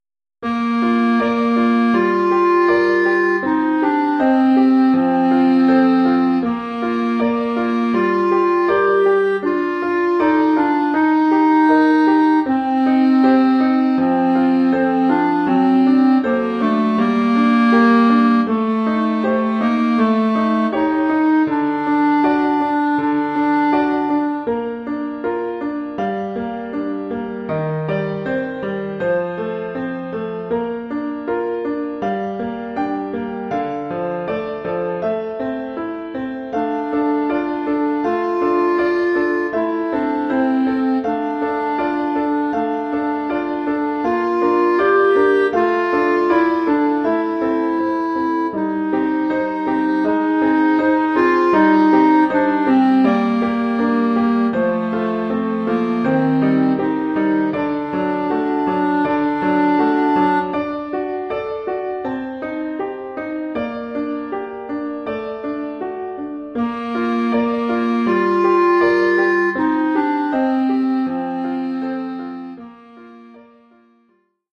Oeuvre pour clarinette et piano.
Niveau : débutant.